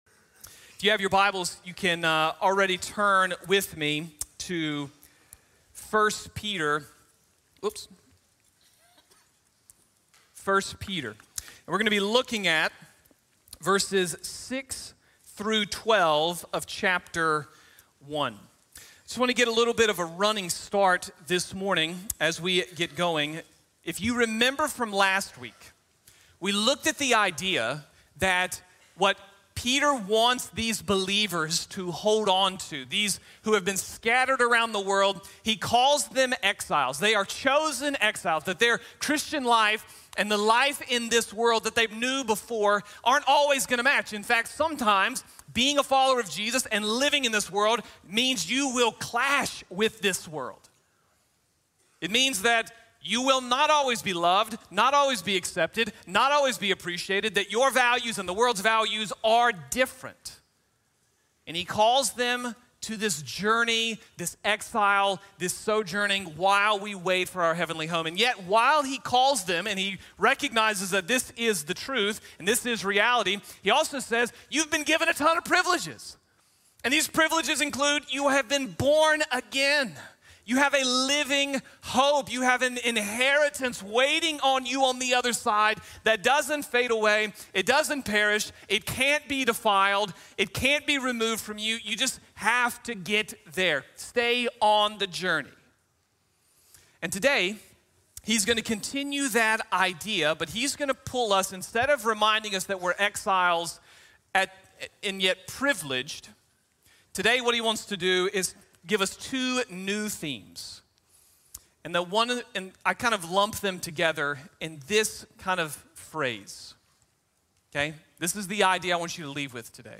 La Salvación Privilegiada Vale Nuestro Sufrimiento Presente | Sermón | Grace Bible Church